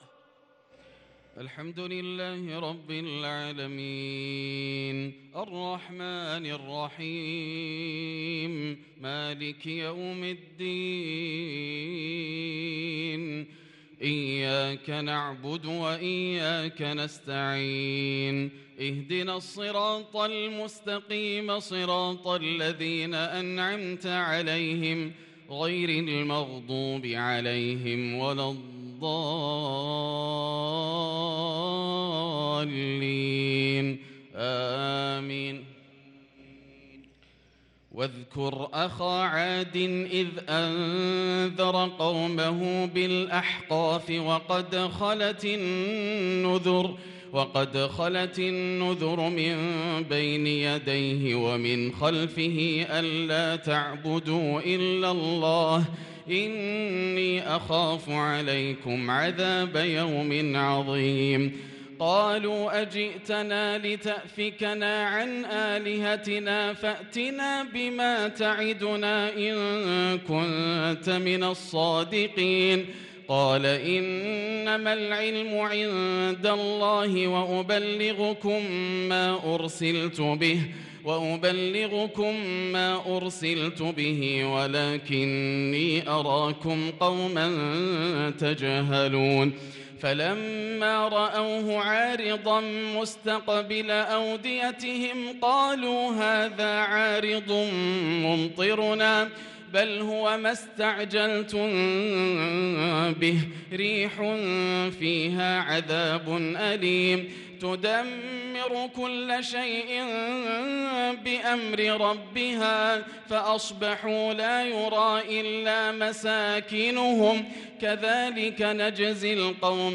صلاة العشاء للقارئ ياسر الدوسري 28 شعبان 1443 هـ
تِلَاوَات الْحَرَمَيْن .